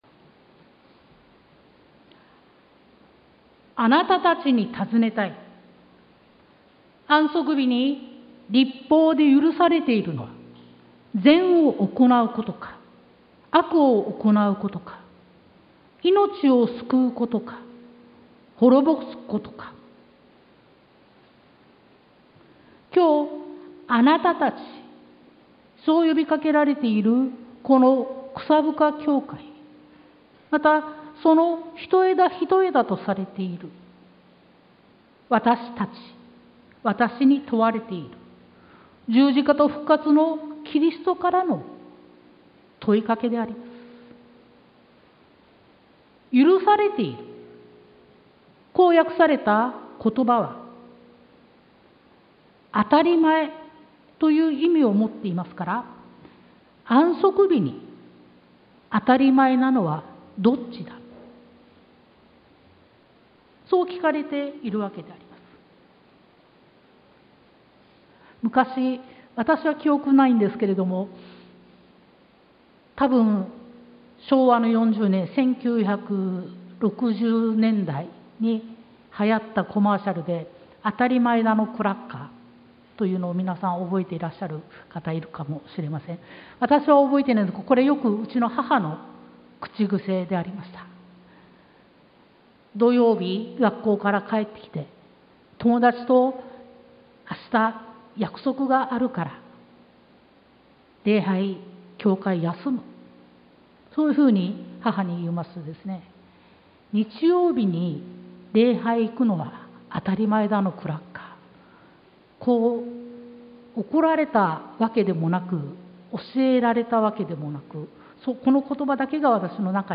sermon-2022-08-14